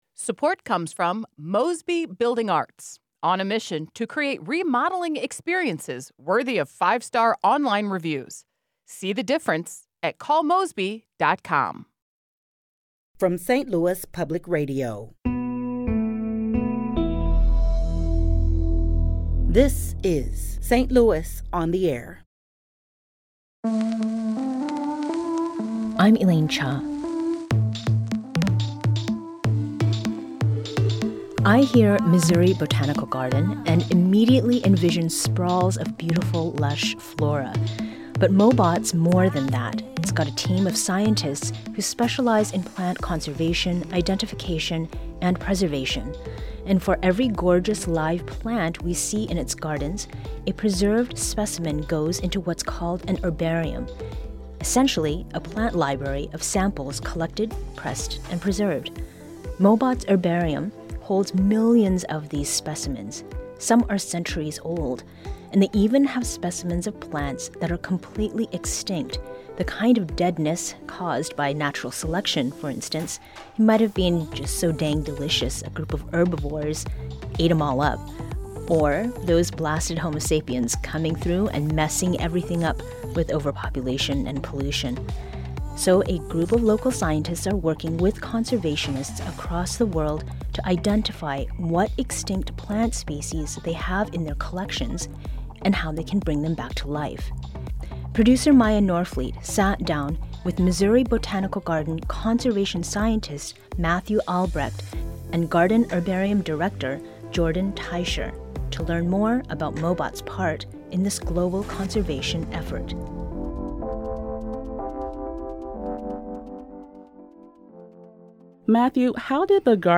On Monday’s St. Louis on the Air, the two scientists shared what is at stake in the global “de-extinction” effort and what part the botanical garden has in the experiment.